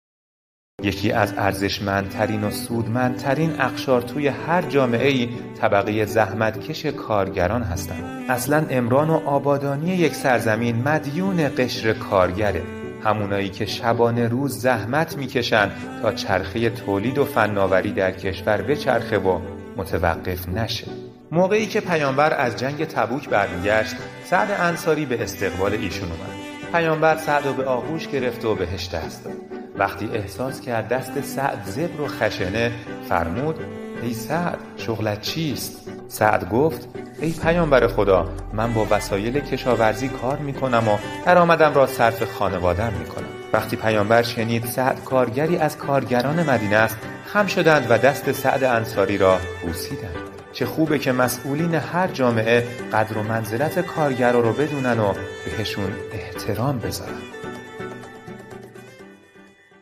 دکلمه‌های زیبا درباره کارگر
دکلمه شماره سه